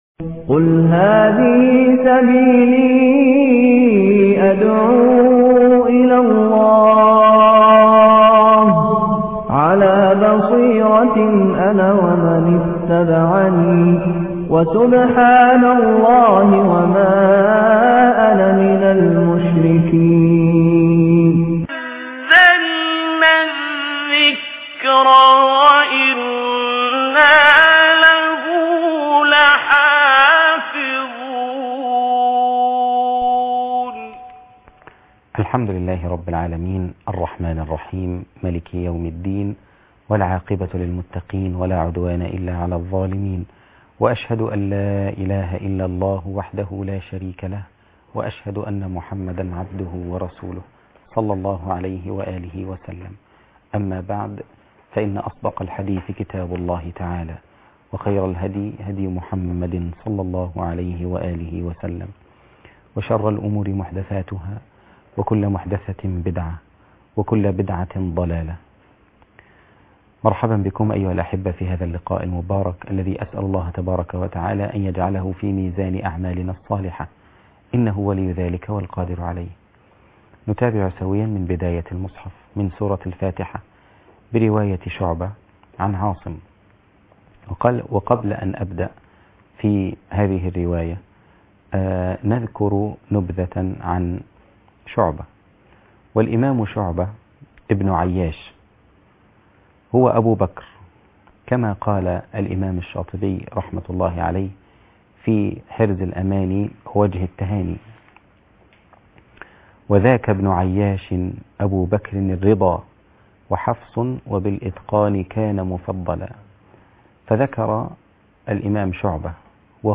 من سورة الفاتحة برواية شعبة
المقرأة